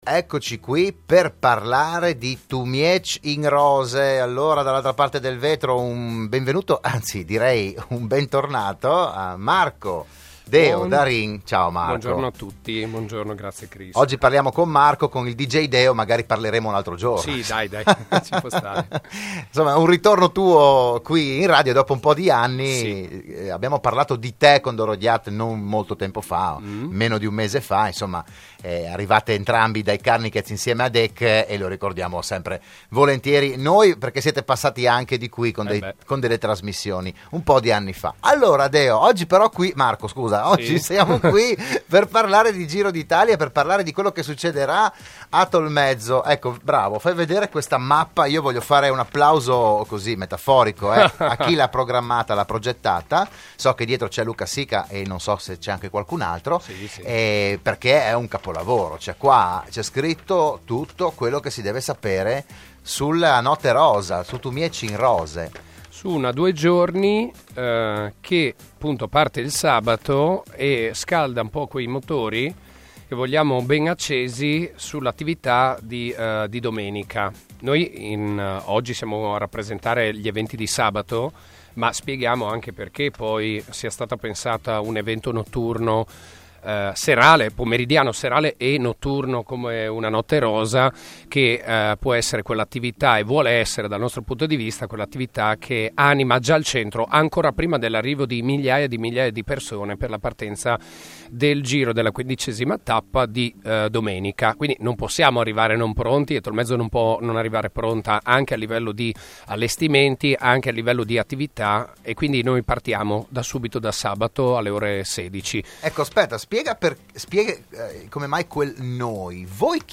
Il PODCAST dell'intervento a "RadioAttiva"